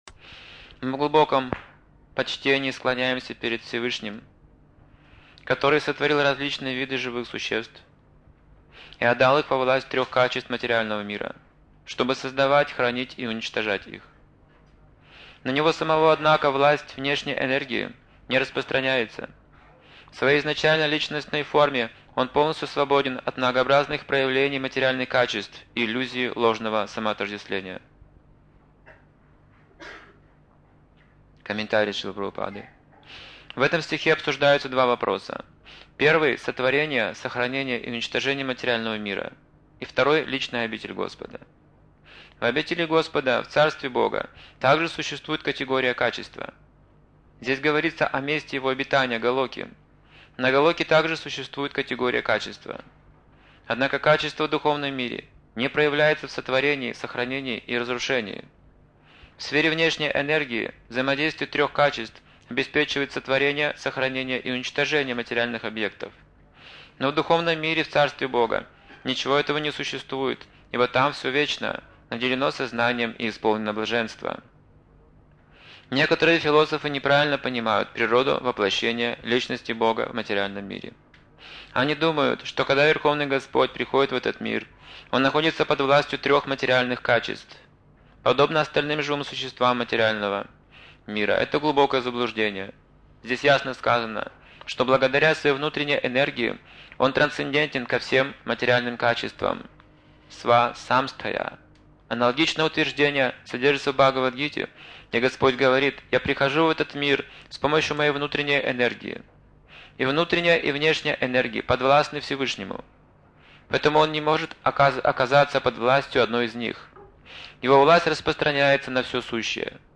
Темы, затронутые в лекции : Желания живых существ Принцип счастья Духовный поиск Предназначение человека Игры Господа Духовное сознание Важность совершать усилия Дар природы Преодоление трудностей Возвращение обратно к Богу История Нарады муни Удовлетворение в разочаровании Психология души